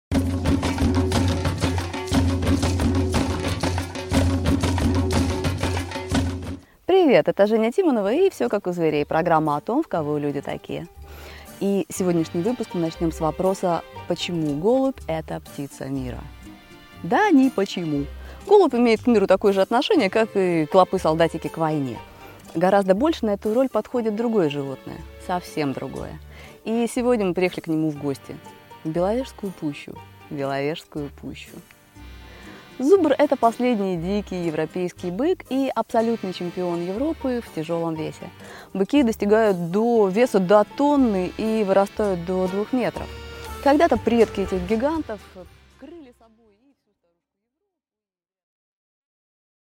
Аудиокнига Зубр, символ мира | Библиотека аудиокниг